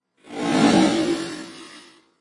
科幻小说中的未来主义声音 " 太空之声 禅 7
描述：科幻科幻外星人机械未来主义
标签： 机械 科幻 科幻 未来 外来
声道立体声